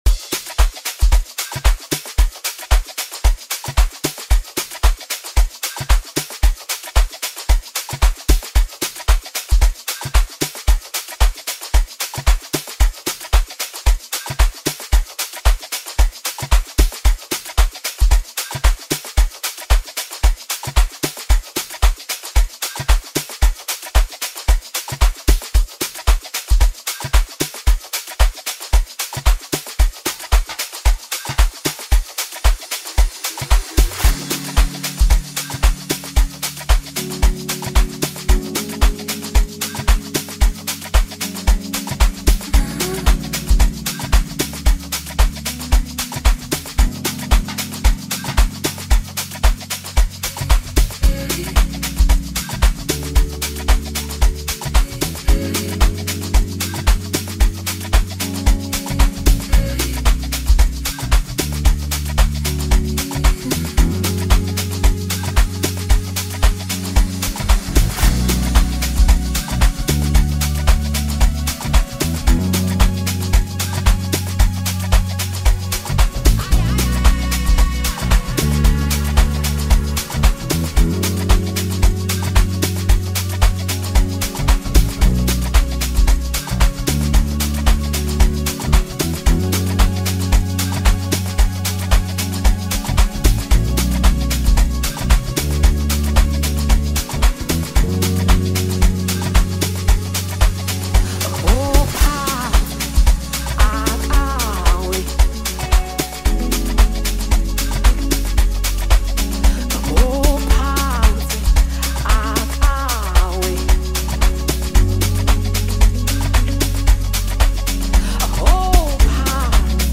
powerful vocals